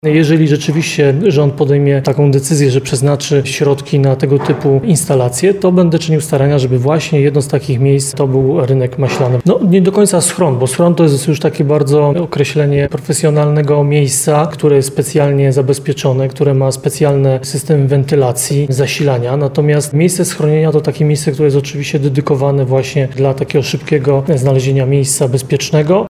Natomiast miejsce schronienia, to miejsce, które jest dedykowane dla szybkiego znalezienia miejsca bezpiecznego – mówi prezydent Nowego Sącza, Ludomir Handzel .